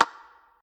spinwheel_tick_06.ogg